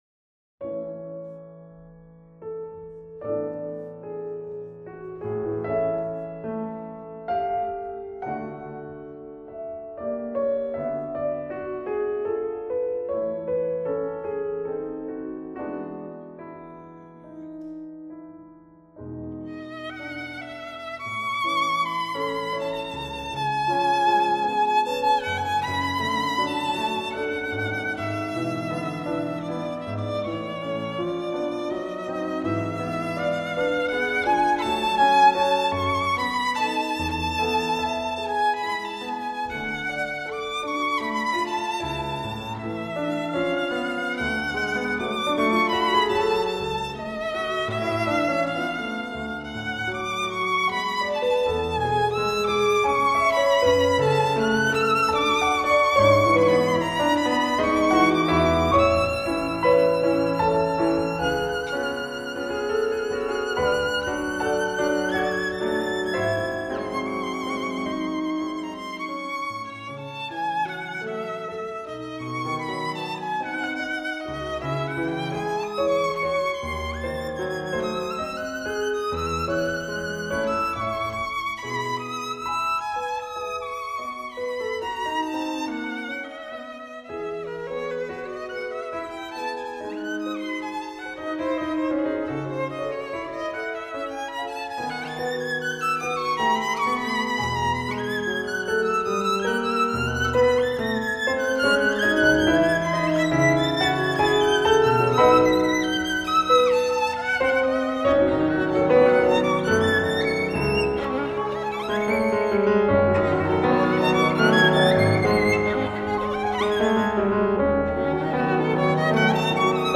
无论从演技到音质都无可挑剔，曲调优美，演奏细腻，丝丝入扣，是发烧友不可多得的一张试音天碟。